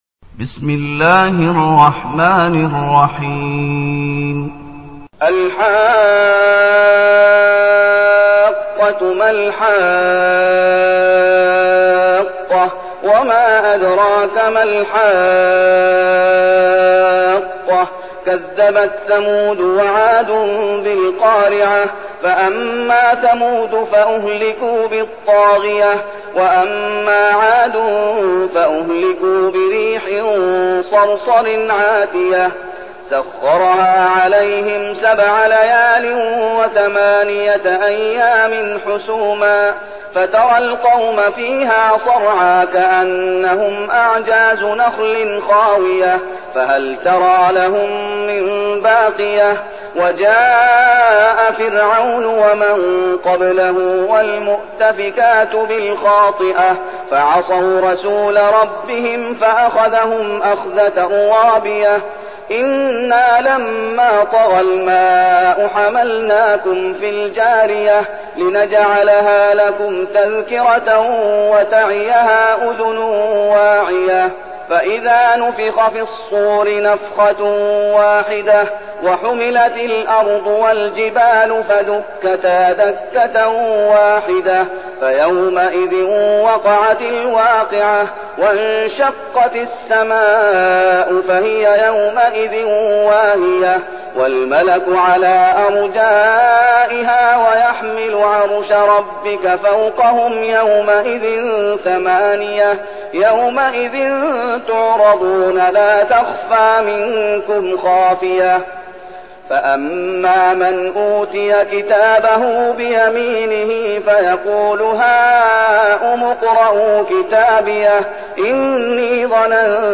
المكان: المسجد النبوي الشيخ: فضيلة الشيخ محمد أيوب فضيلة الشيخ محمد أيوب الحاقة